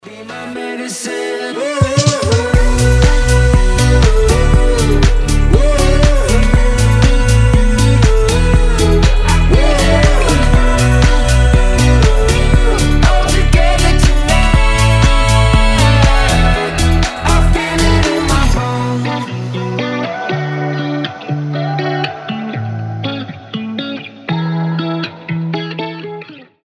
• Качество: 320, Stereo
позитивные
легкие
бодрые